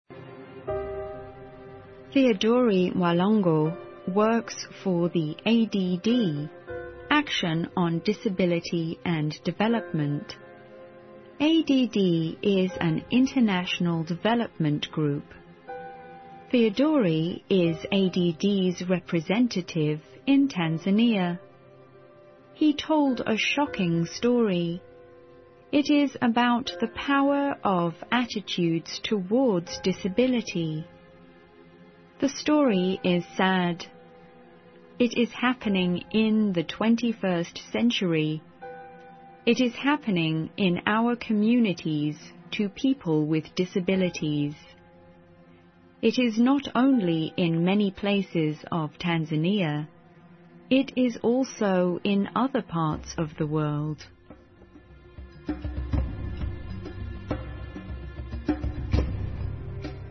英语资讯